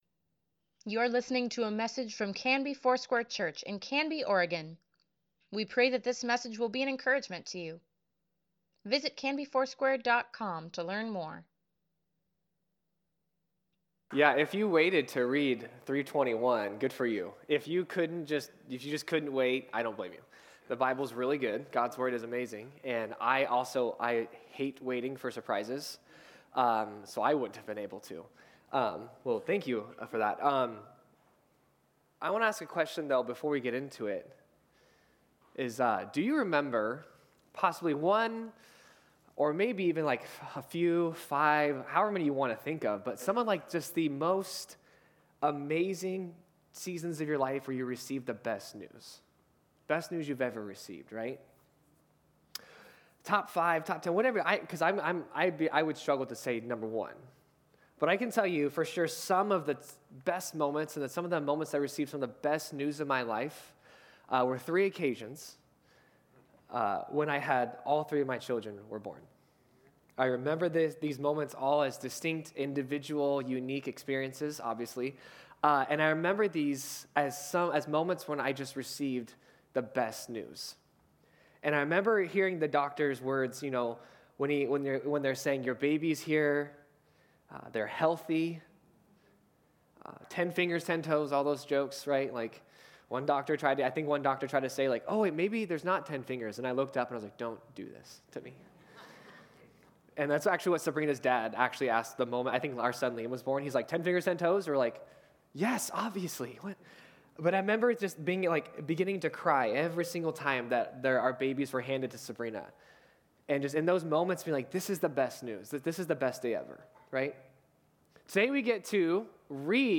Sunday Sermon | March 17, 2024